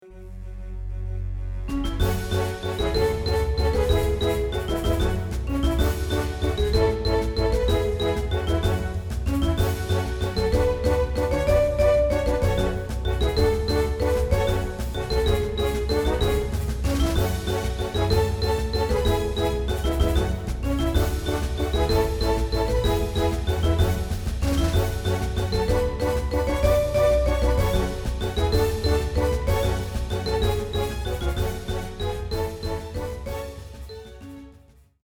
Genre film / musical
• instrumentatie: Viool